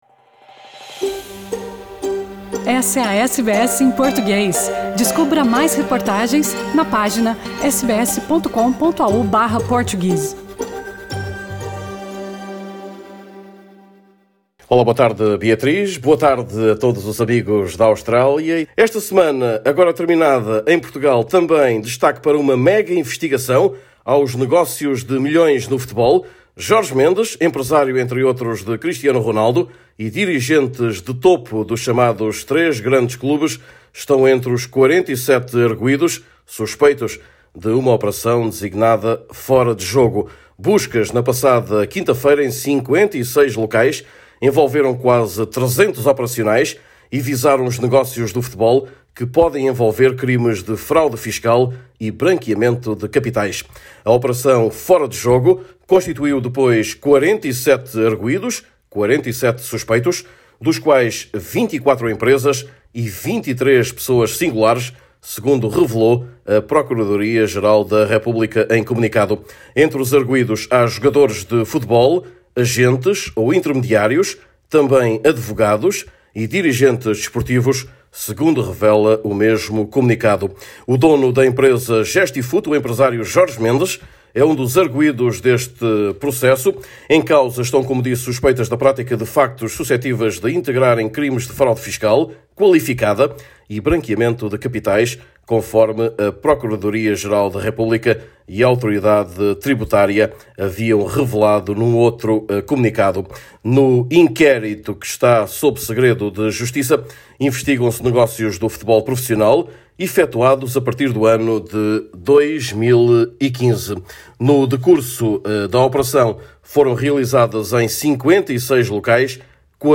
Neste boletim semanal